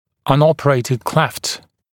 [ʌn’ɔpəreɪtɪd kleft][ан’опэрэйтид клэфт]неоперированная расщелина